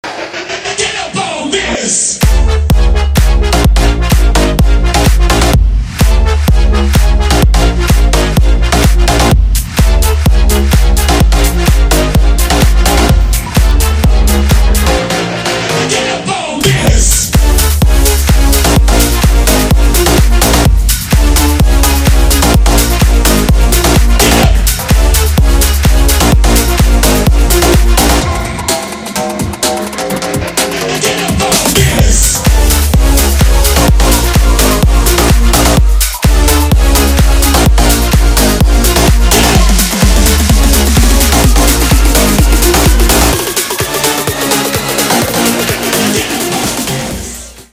• Качество: 320, Stereo
future house
клубнячок